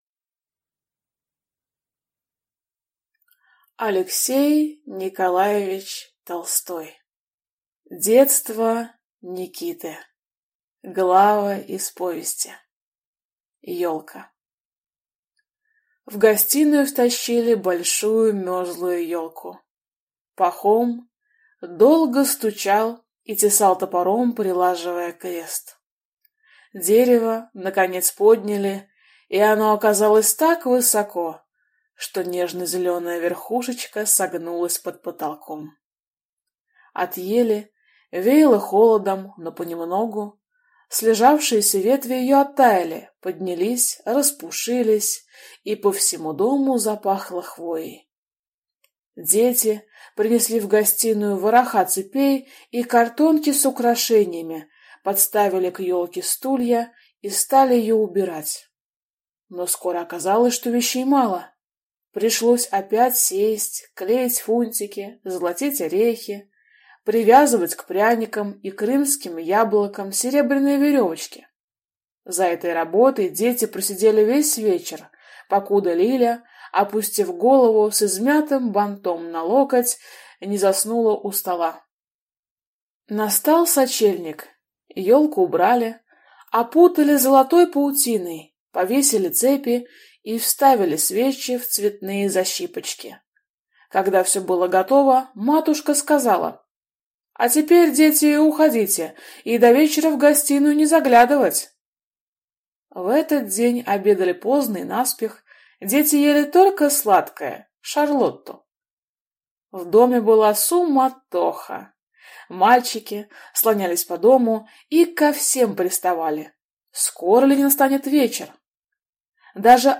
Аудиокнига Детство Никиты (главы из повести) | Библиотека аудиокниг